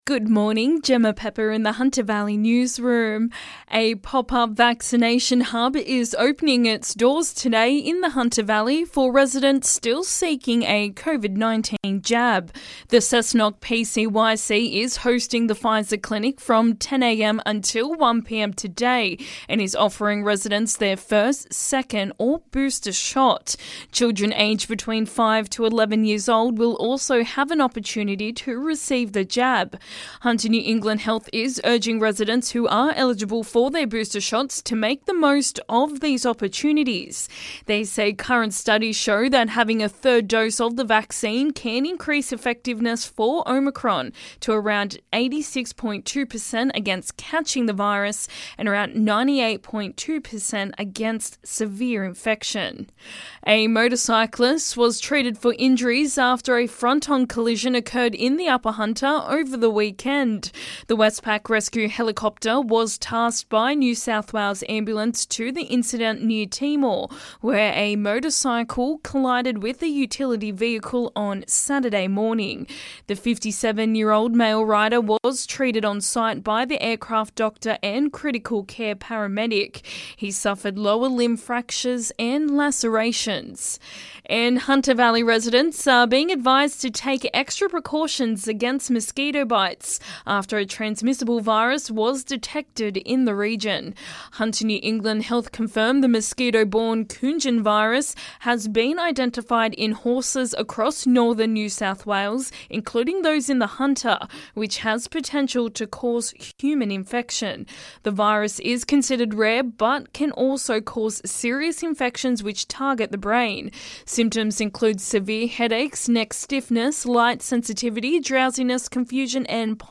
Hunter Valley Local Morning News Headlines 31/01/2022